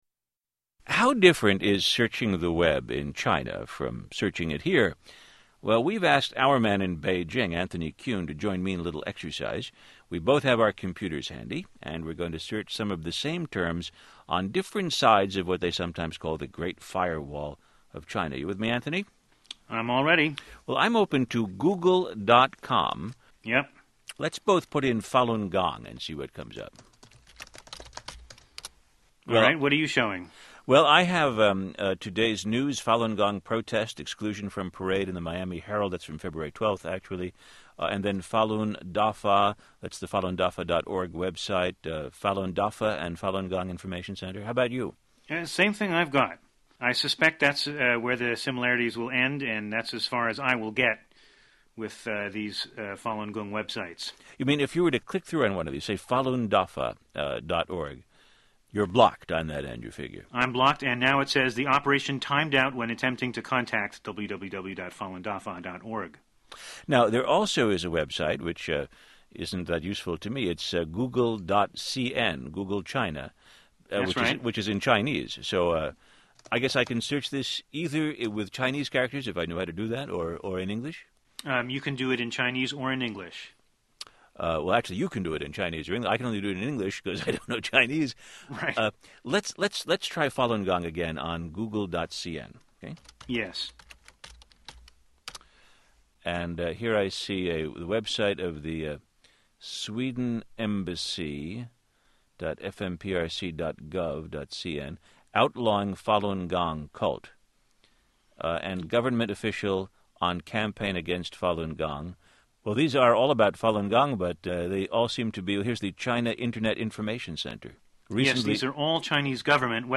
Web surfing in China This excerpt is from NPR's All Things Considered program of February 15, 2006. Two correspondents -- one in the US and one in China -- surf the Web using Google and Yahoo.